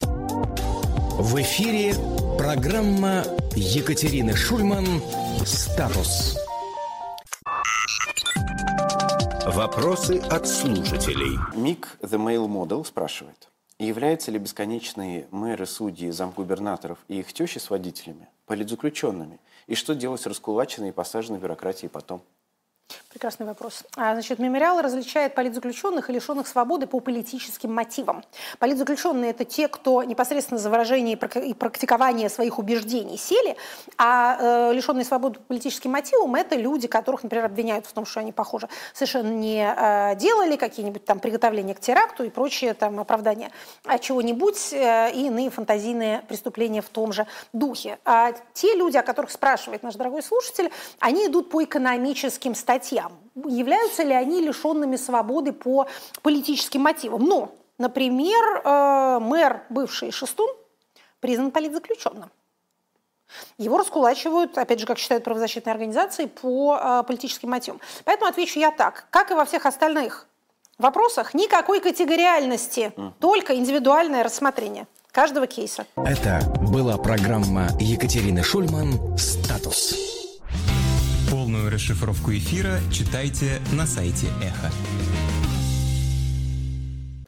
Екатерина Шульманполитолог
Фрагмент эфира от 09.12.25